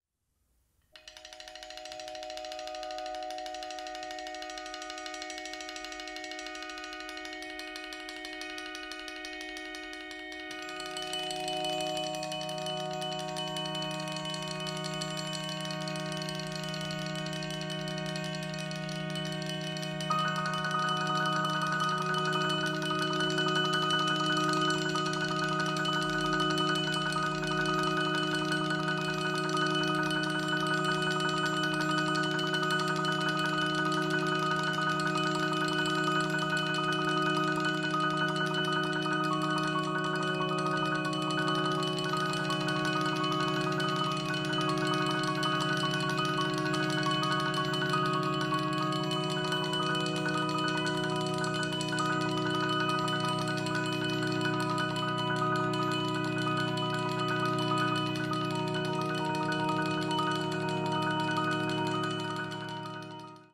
two percussionists and piano